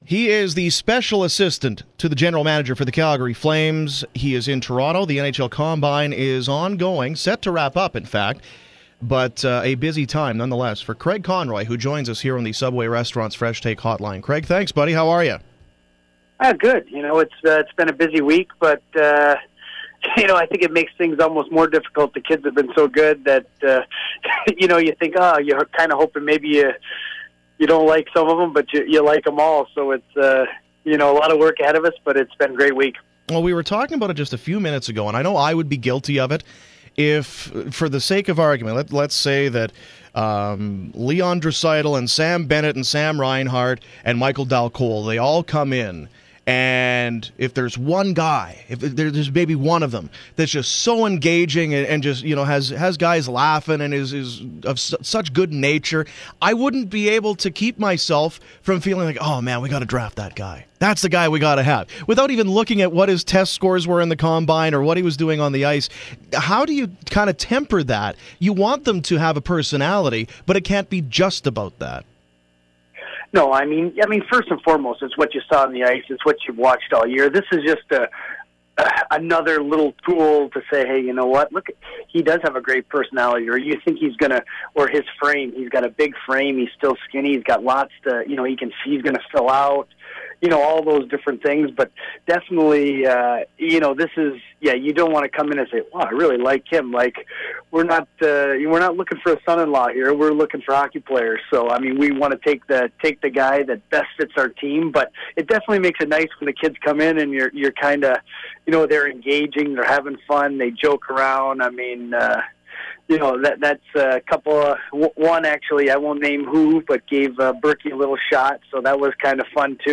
Conroy interview from the draft combine: